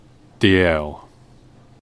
Detective Superintendent Andrew "Andy" Dalziel /dˈɛl/